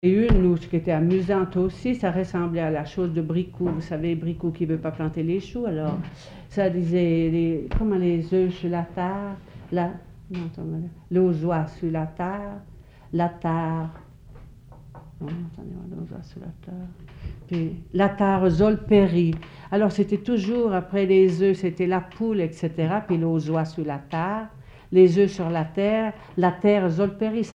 Mémoires et Patrimoines vivants - RaddO est une base de données d'archives iconographiques et sonores.
Comptine en francoprovençal et commentaire